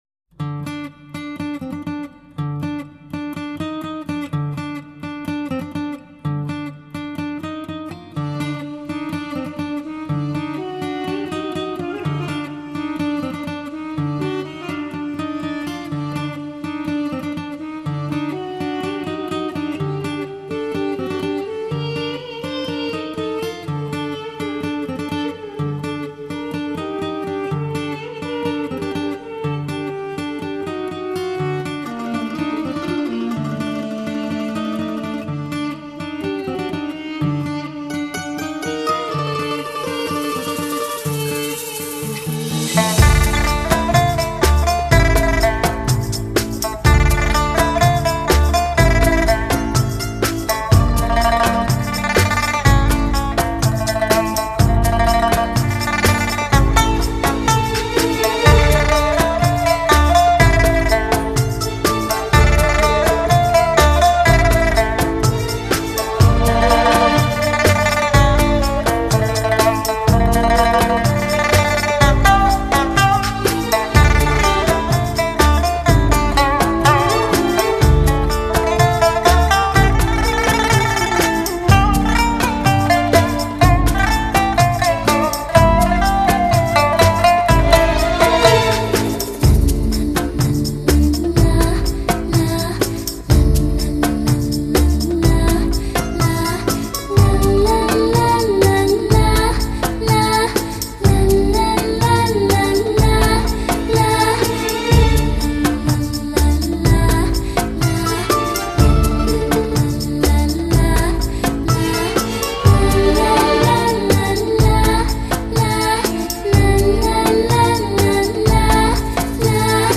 独具韵味 大器澎湃
琵琶声如远方缓缓走来摇曳着蛇舞的少女。